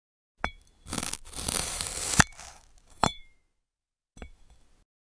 A zombie cutting a Snow sound effects free download
A zombie cutting a Snow Pea.